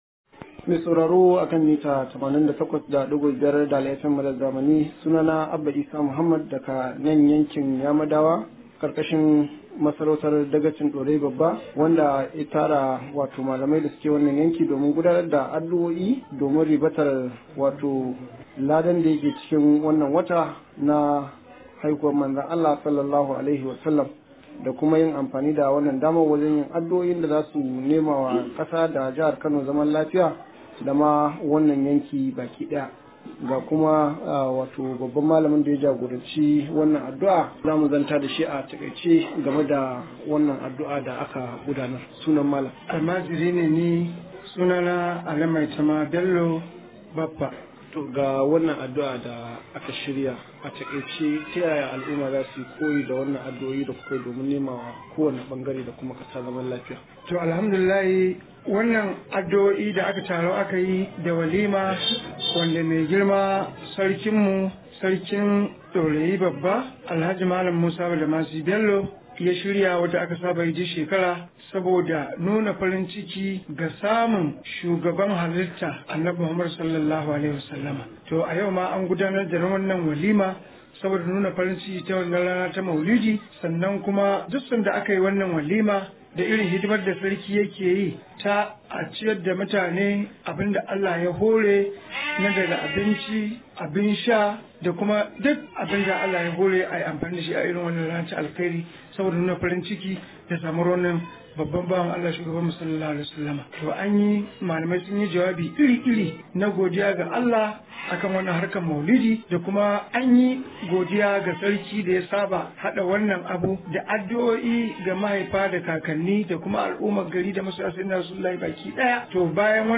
Alhaji Musa, ya bayyana hakan ne, a yayin bikin addu’o’in zaman lafiya da kuma Maulidi, wanda ya gudana a fadar Dagacin Dorayi.